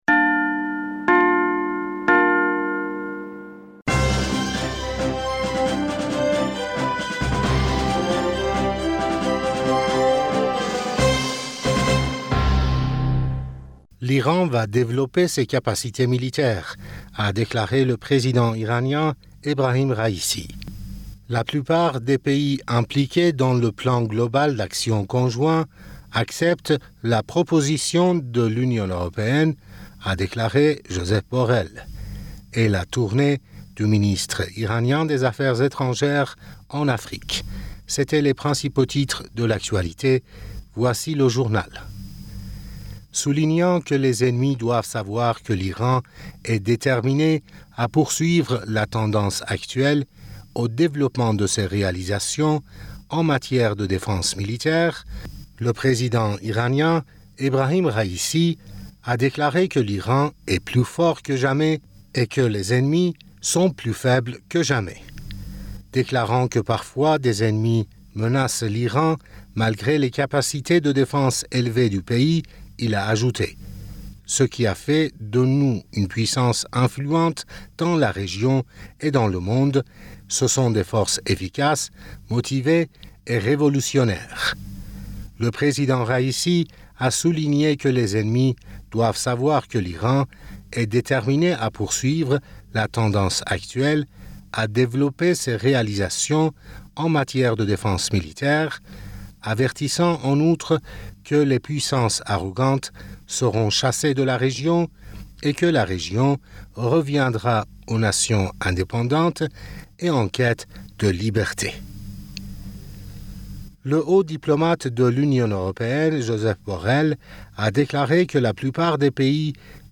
Bulletin d'information Du 23 Aoùt